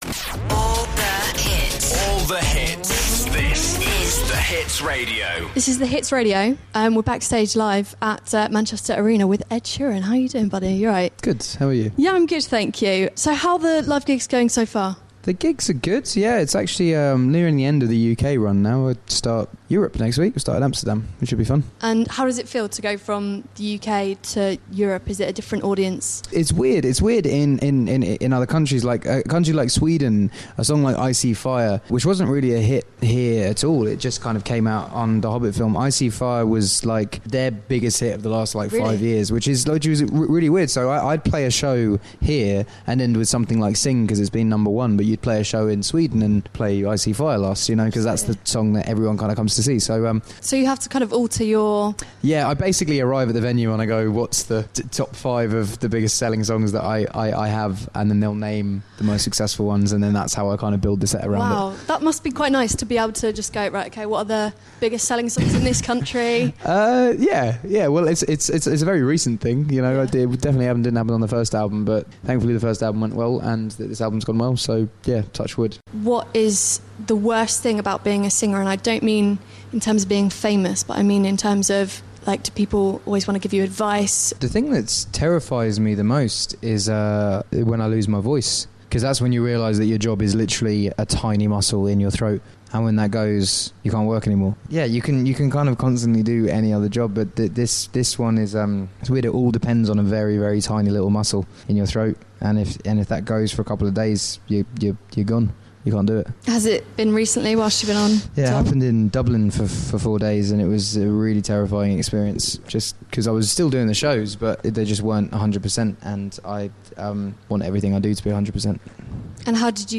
caught up with Ed Sheeran before he went onstage to perform LIVE in Manchester!